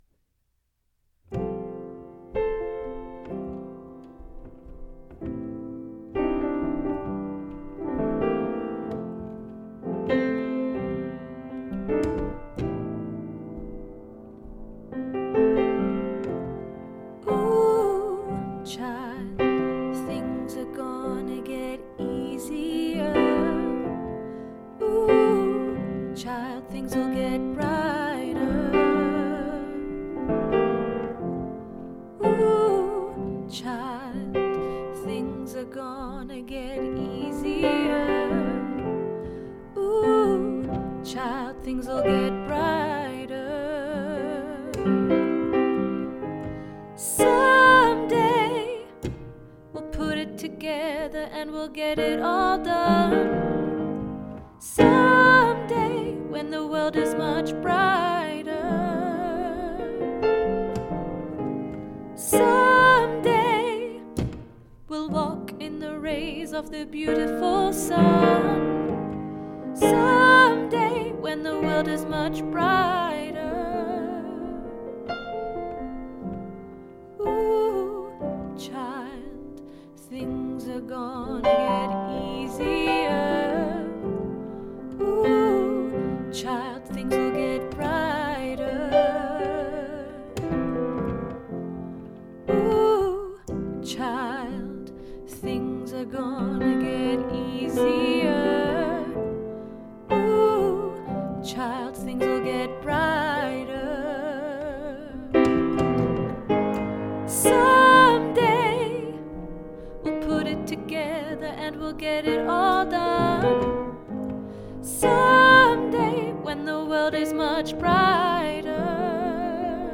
Ooh Child Soprano